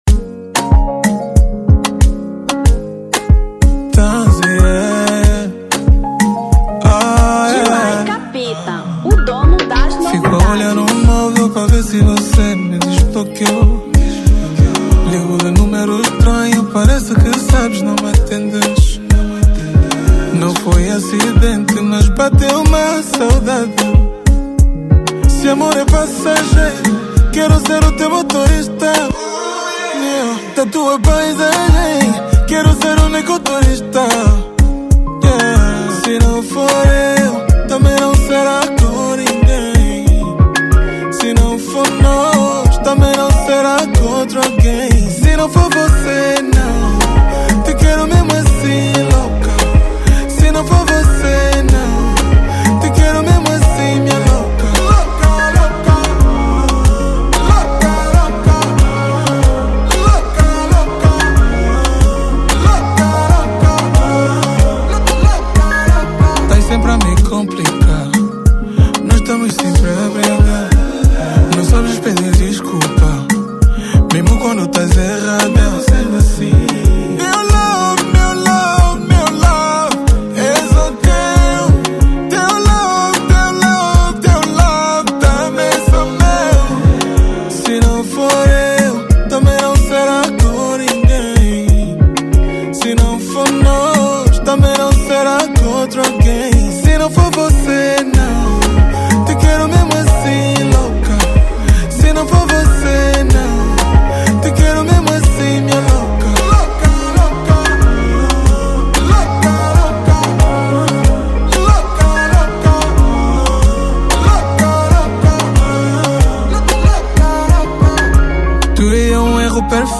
Kizomba 2024